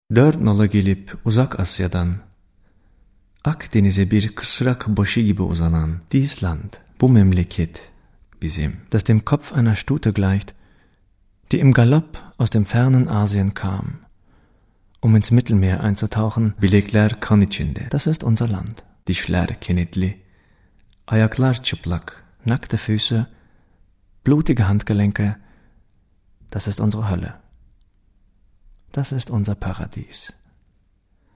Sprecher türkisch für Werbung, Industrie, E-Learning, Imagefilme,
Sprechprobe: Industrie (Muttersprache):
turkish voice over artist